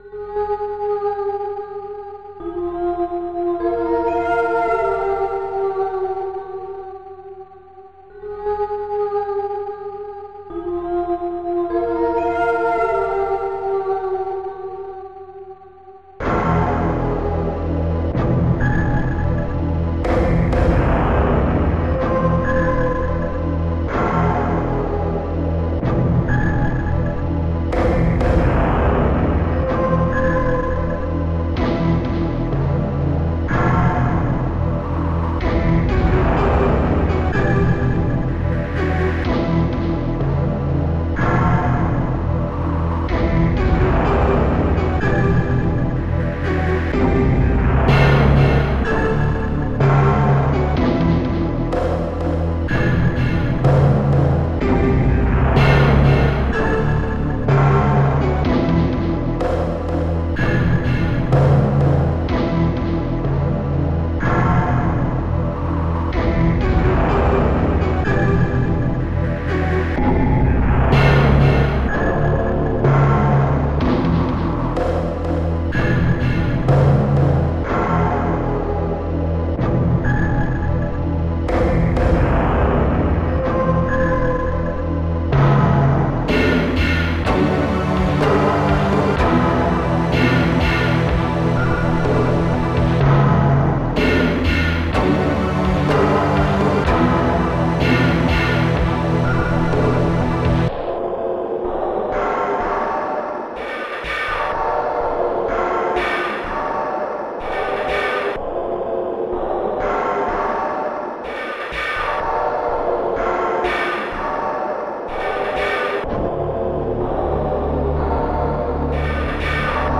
Protracker Module  |  2000-10-02  |  123KB  |  2 channels  |  44,100 sample rate  |  2 minutes, 52 seconds
Protracker and family